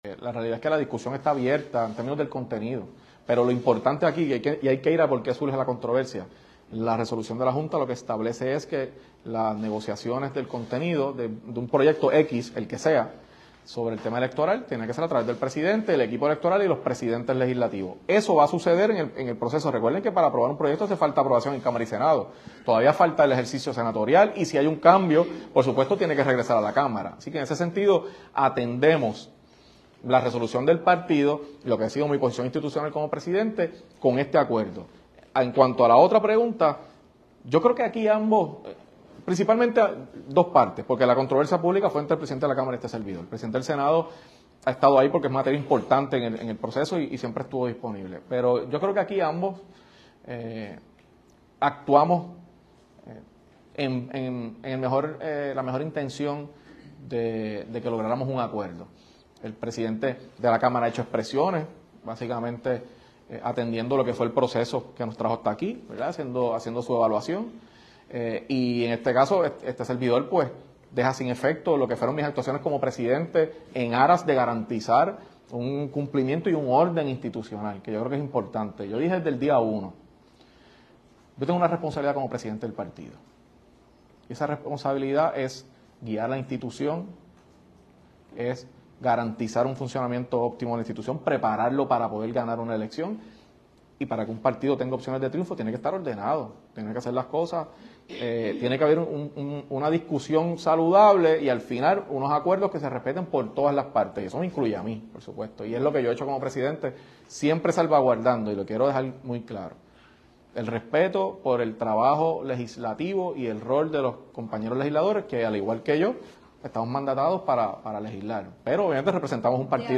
Y el gobernador tiene que entender que el hecho de que tenga, y su partido, una mayoría que le da la posibilidad de gobernar, eso no significa que son dueños y señores del proceso de mejorar el sistema electoral”, dijo Ortiz González en rueda de prensa.